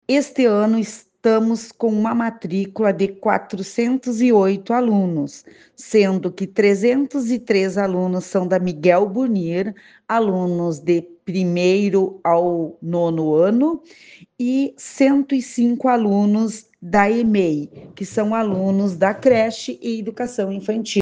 Segundo a secretária municipal de Educação, Cultura, Desporto e Lazer, Susana Tissot Wilde, no total a rede municipal tem 408 alunos. (Abaixo, sonora de Susana Wilde)